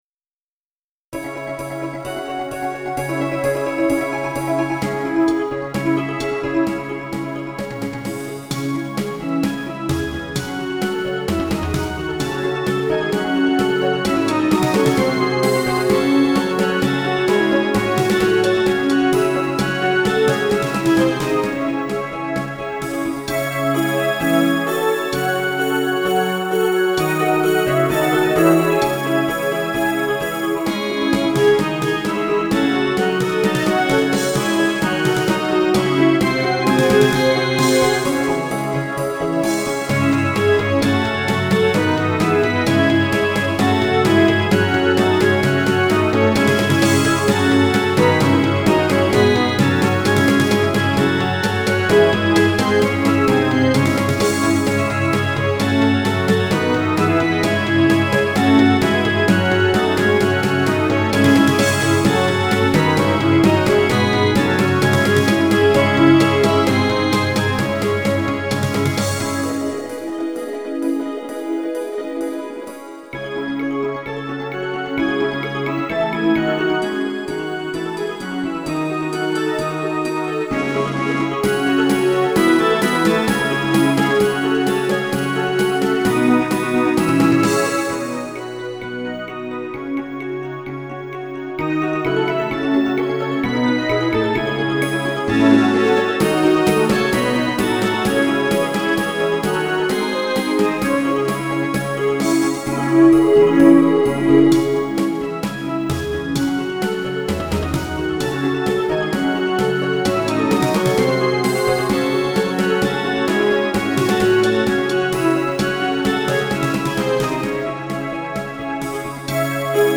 嬰ハ短調